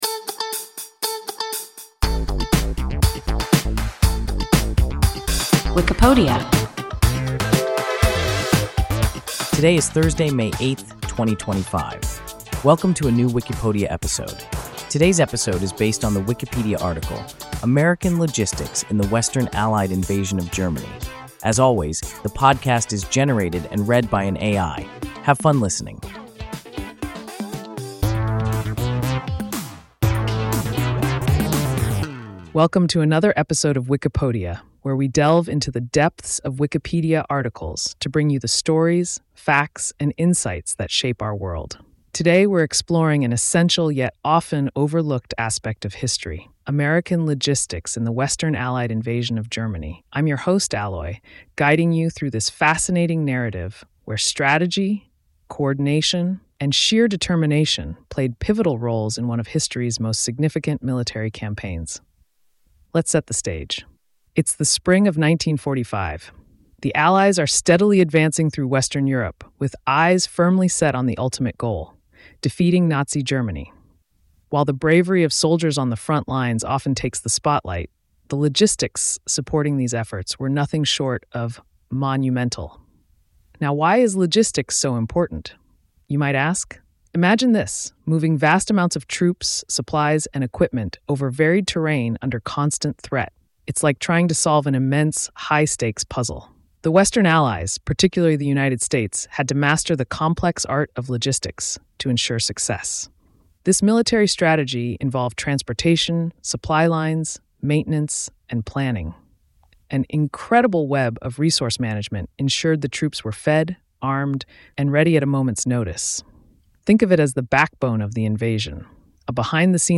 American logistics in the Western Allied invasion of Germany – WIKIPODIA – ein KI Podcast